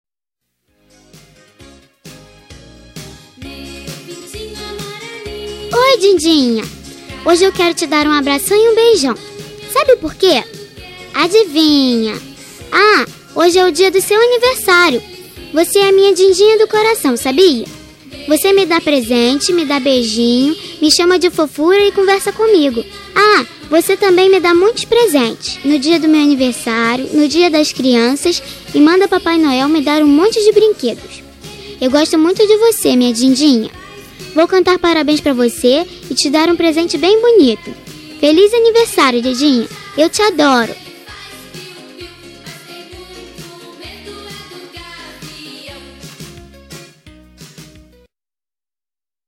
Aniversário Voz Infantil Madrinha – Voz Feminina – Cód: 431125